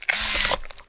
rifle_raise.wav